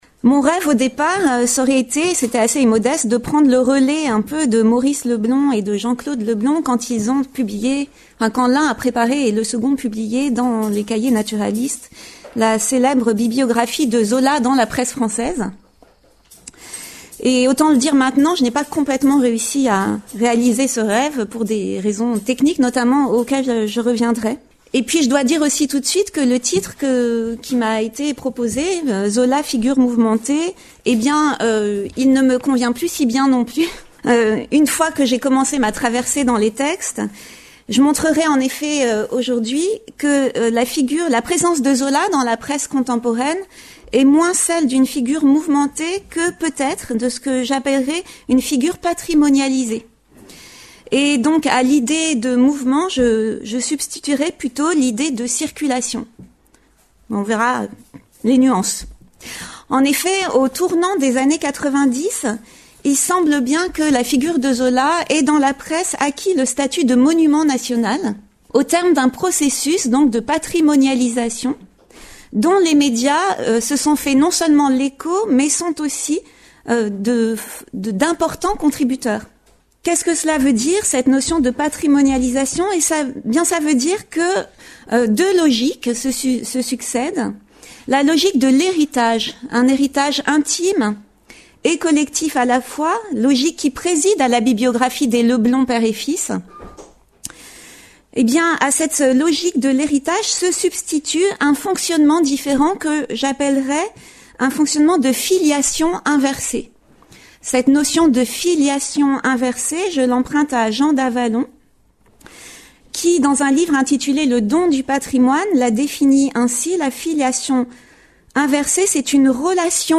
Comment le souvenir de l’œuvre et de la figure zoliennes hantent-ils nos quotidiens, au tournant des XXe et XXIe siècle? En analysant l’ensemble des titres de la presse française exploités dans la base de données Europresse, cette conférence interroge les formes d’un processus de patrimonialisation, au cours duquel l’image d’un écrivain engagé et clivant se transforme peu à peu en monument national.